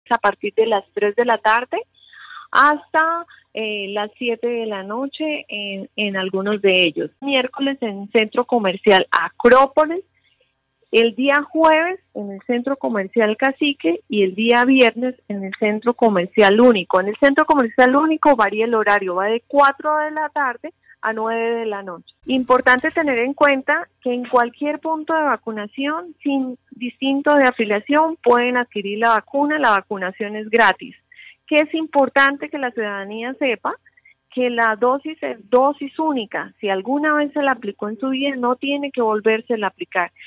Liliana Serrano, subsecretaria de Salud de Bucaramanga